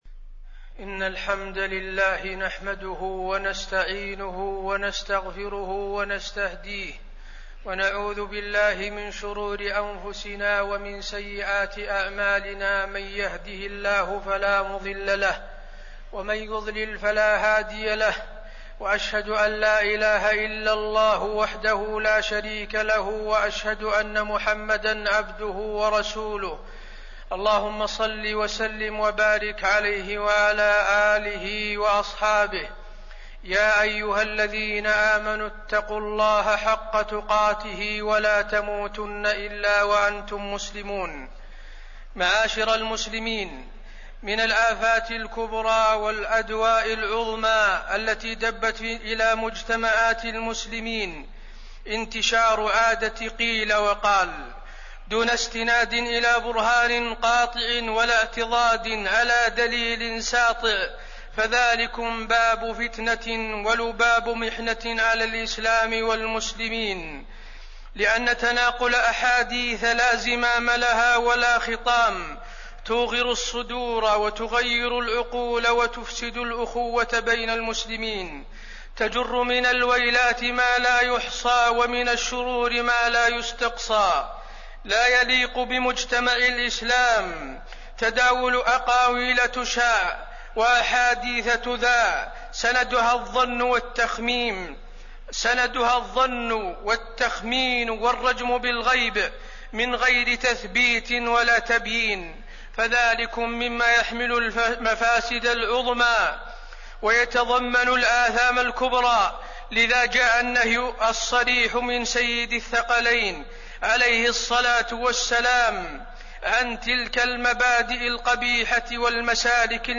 تاريخ النشر ٢١ ذو القعدة ١٤٣١ هـ المكان: المسجد النبوي الشيخ: فضيلة الشيخ د. حسين بن عبدالعزيز آل الشيخ فضيلة الشيخ د. حسين بن عبدالعزيز آل الشيخ التحذير من الخوض في الأعراض